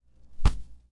音频1 " 12 Golpe Personaje Sec
描述：Foley Final Audio1 2018